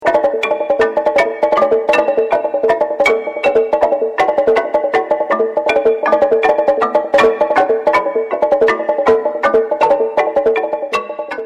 GROOVE 1 GROOVE 2